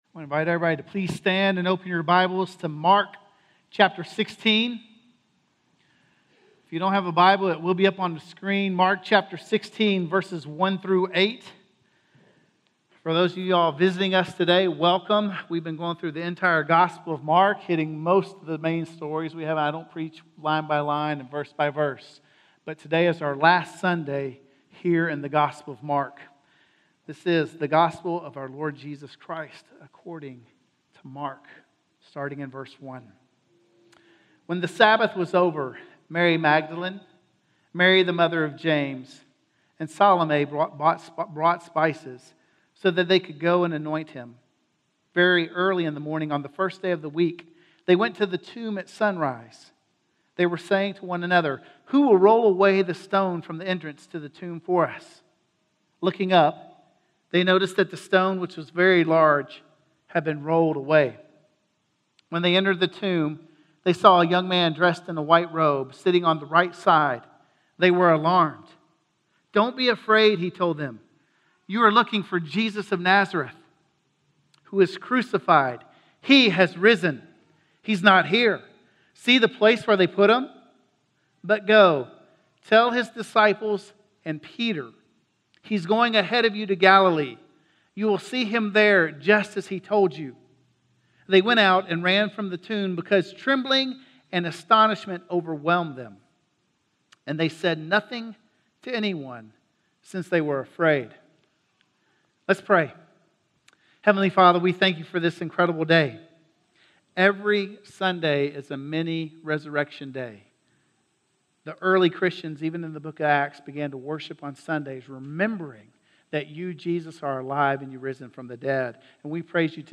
Resurrection - Sermon - Woodbine